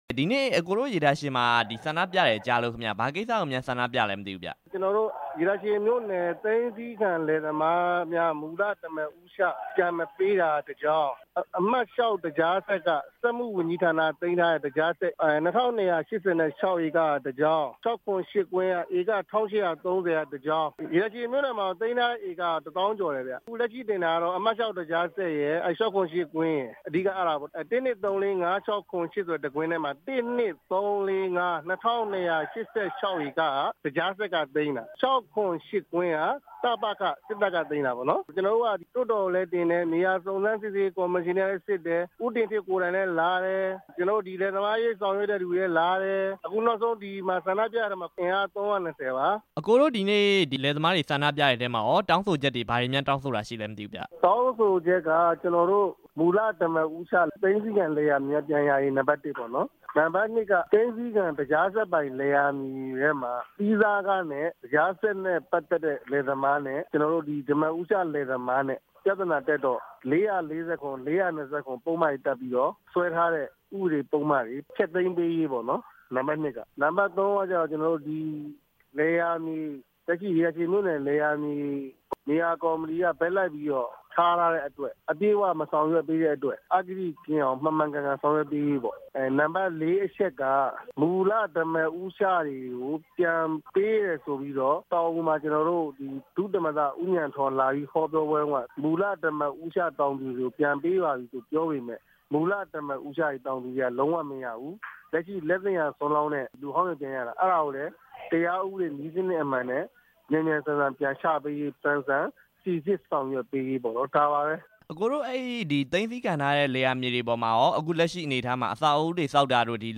ဆက်သွယ်မေးမြန်းထားပါတယ်။